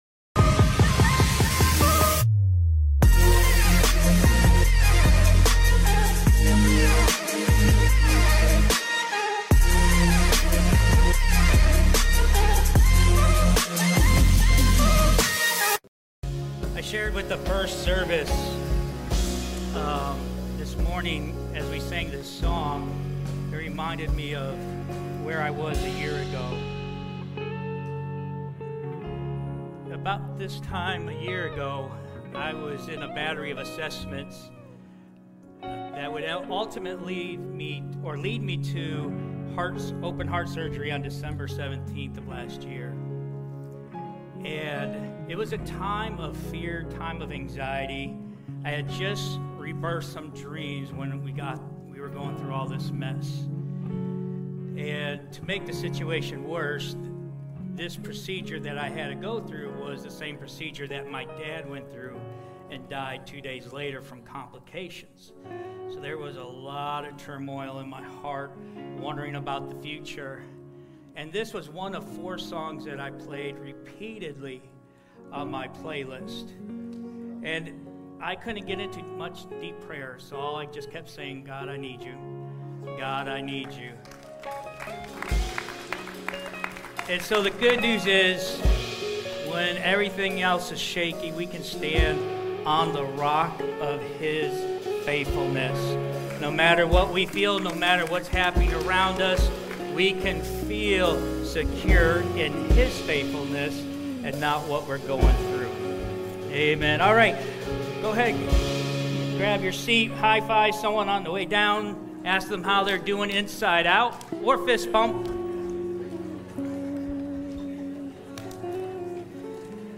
The baptism at the end beautifully illustrates what it means to fix the wobble in our lives—to shore up the unbalanced legs of our existence so we can walk steadily in our calling.